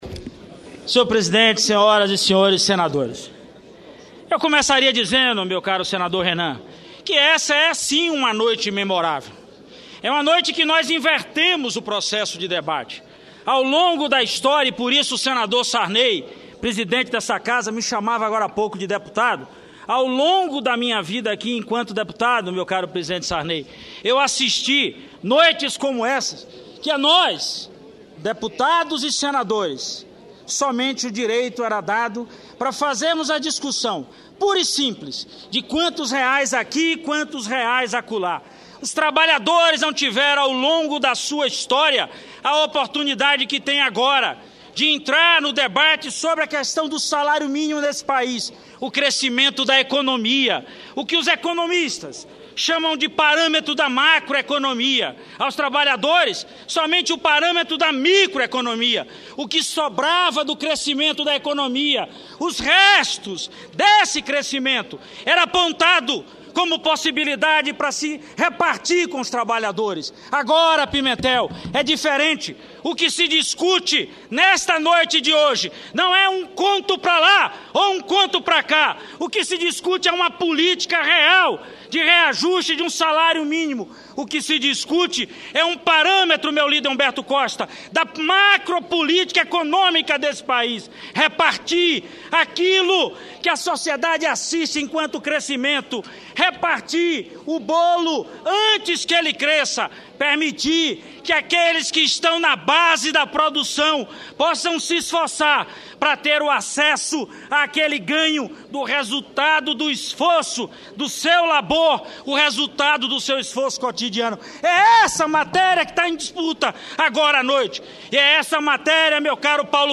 Discurso do senador Walter Pinheiro